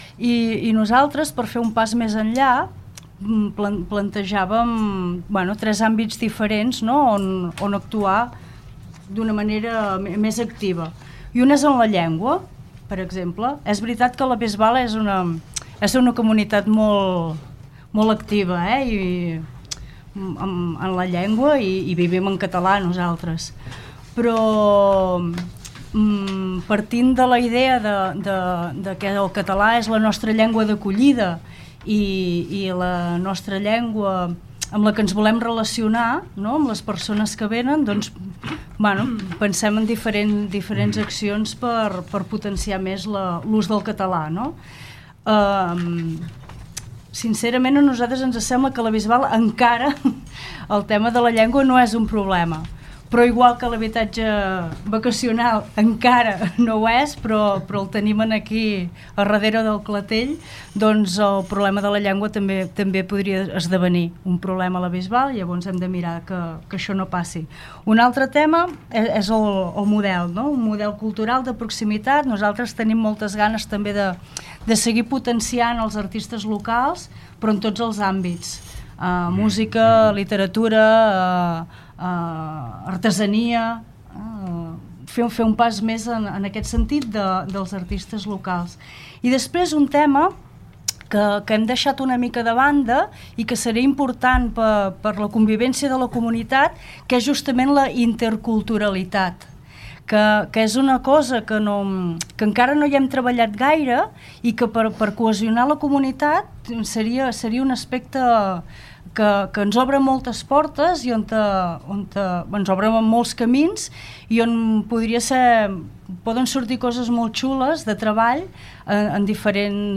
Els candidats a l'alcaldia de La Bisbal d'Empordà han visitat els estudis de Ràdio Capital per parlar cara a cara d'alguns dels temes més rellevants del present i futur del municipi.